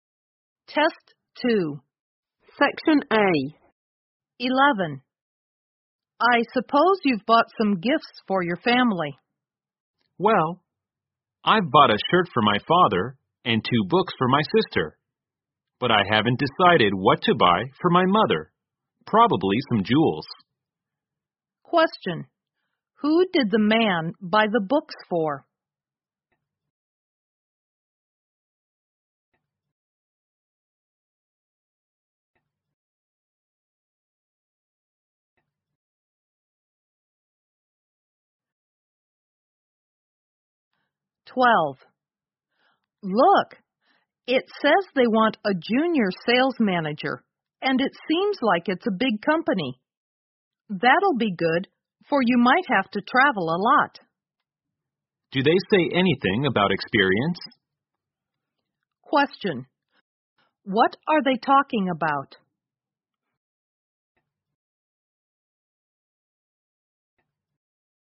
在线英语听力室005的听力文件下载,英语四级听力-短对话-在线英语听力室